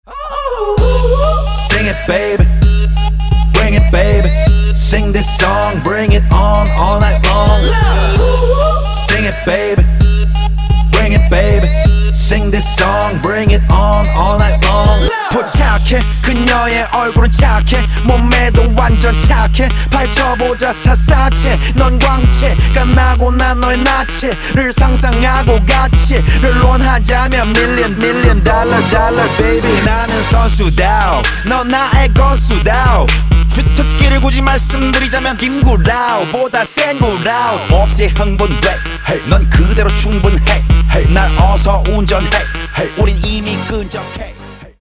• Качество: 192, Stereo
club
Rap
клубные